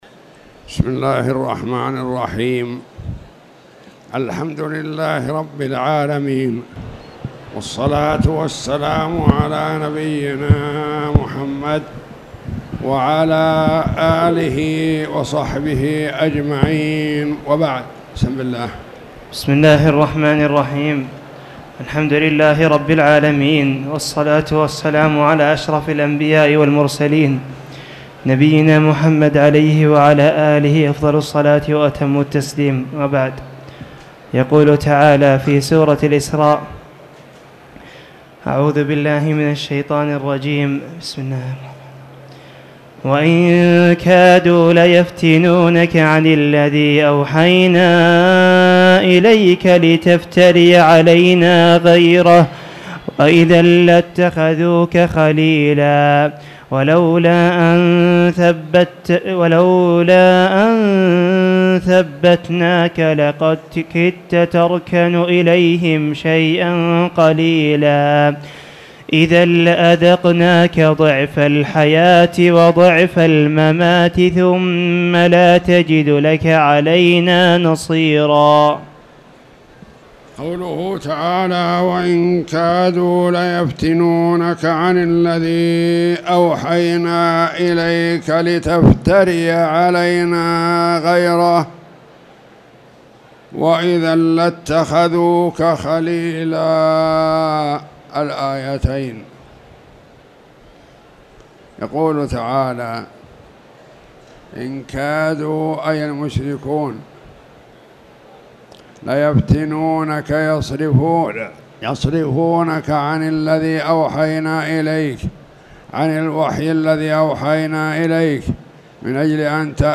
تاريخ النشر ٢٣ شوال ١٤٣٧ هـ المكان: المسجد الحرام الشيخ